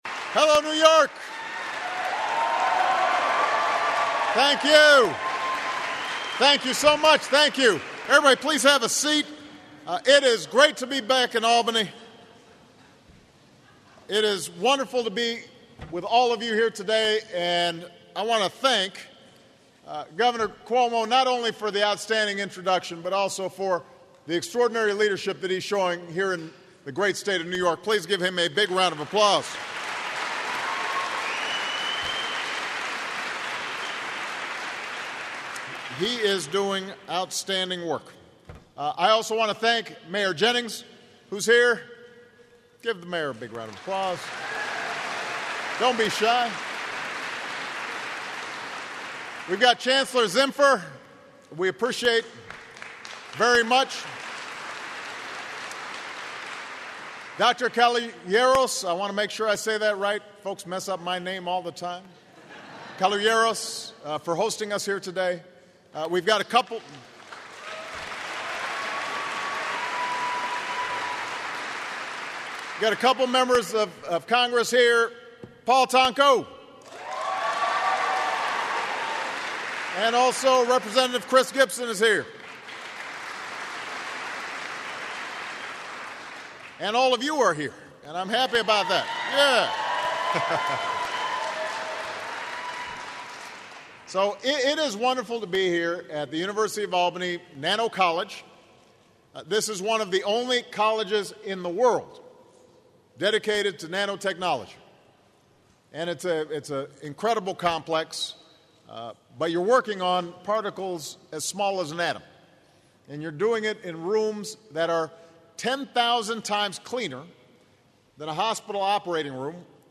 U.S. President Barack Obama speaks at the College of Nanoscale Science and Engineering, University at Albany-SUNY
Obama acknowledges the number of high-tech firms operating in upstate New York and declares that the private sector must create jobs. Obama speaks at the College of Nanoscale Science and Engineering, University at Albany-SUNY.